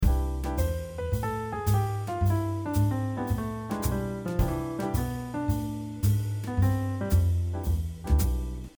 4. Using the bebop scale
For example, a C Major bebop scale would be: C, D, E, F, G, Ab, A, B.
Using-the-bebop-scale.mp3